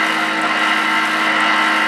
PlasmaCutterLoop.ogg